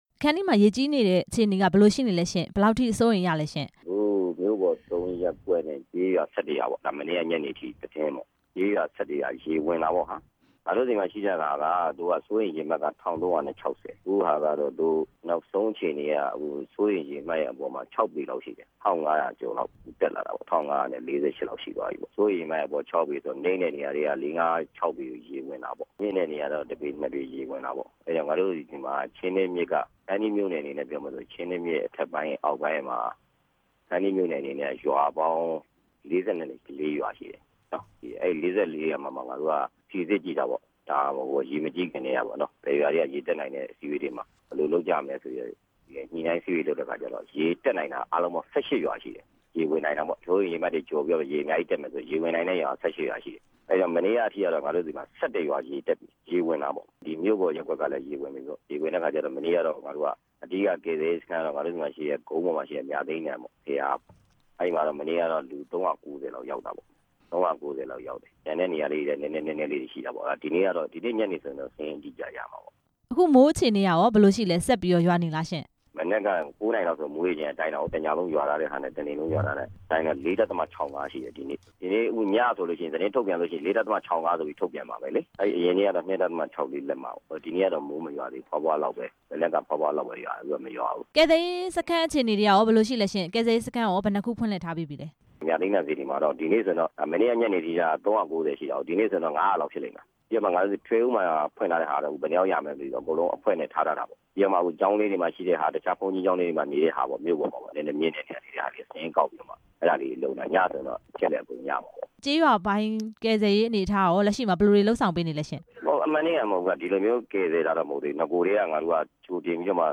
ခန္တီးမြို့နယ် ရေဖုံးလွှမ်းနေတဲ့အခြေအနေ မေးမြန်းချက်